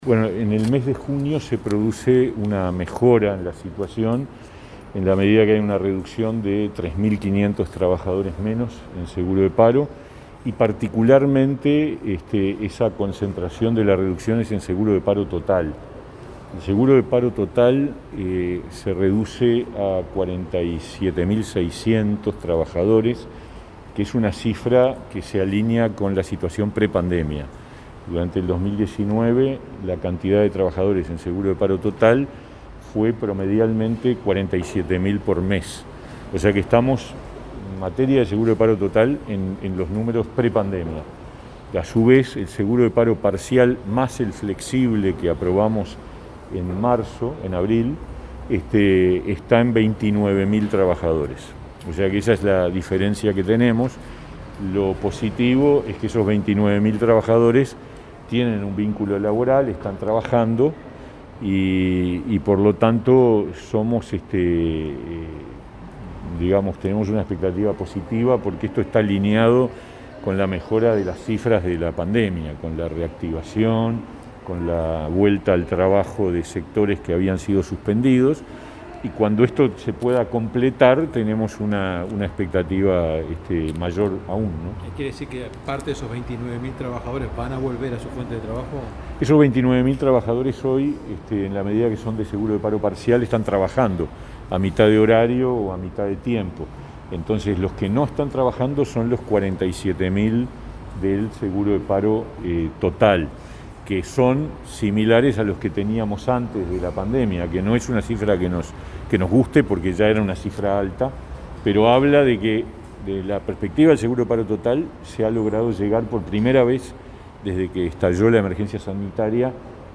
Declaraciones del ministro de Trabajo y Seguridad Social, Pablo Mieres, en Torre Ejecutiva
Declaraciones del ministro de Trabajo y Seguridad Social, Pablo Mieres, en Torre Ejecutiva 13/07/2021 Compartir Facebook Twitter Copiar enlace WhatsApp LinkedIn Este martes 13, el ministro de Trabajo y Seguridad Social, Pablo Mieres, brindó declaraciones de prensa acerca de la reducción en la cantidad de trabajadores en seguro de paro.